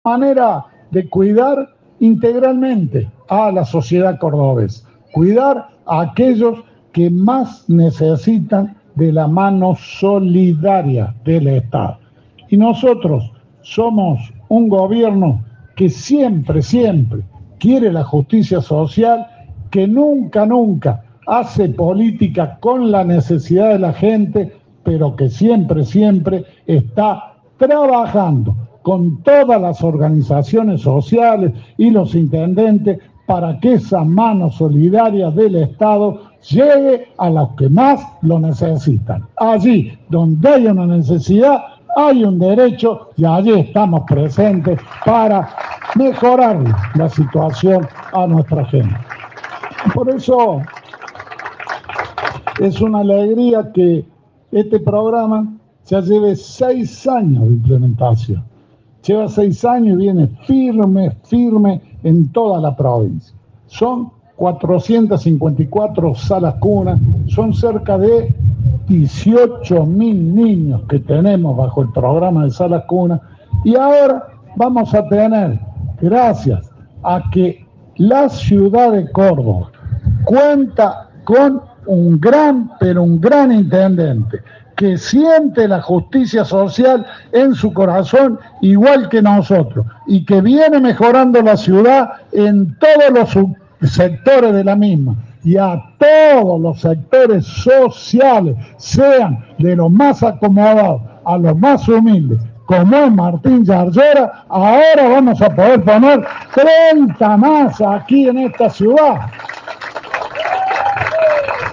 Durante el acto, se suscribió el convenio de adhesión al programa Salas Cuna entre el Ministerio de Desarrollo Social y la Municipalidad capitalina, rubricado por el gobernador.
Audio: Juan Schiaretti (Gobernador de Córdoba).